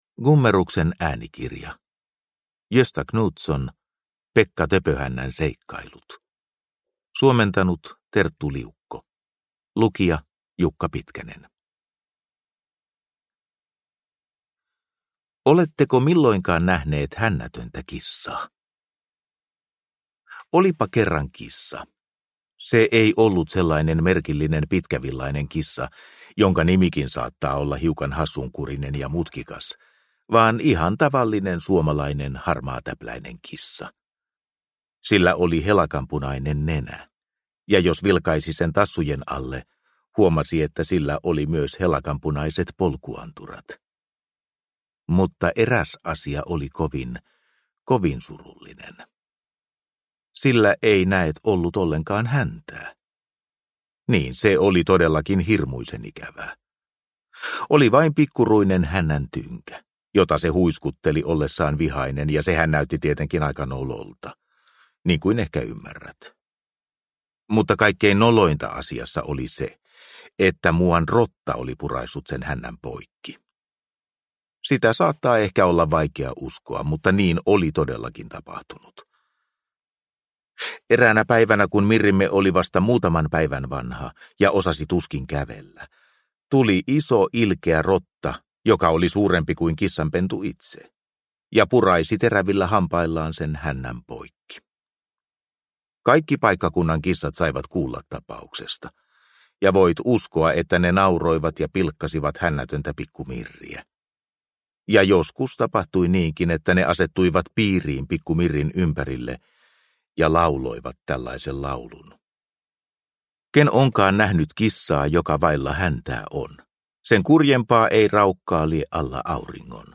Pekka Töpöhännän seikkailut – Ljudbok – Laddas ner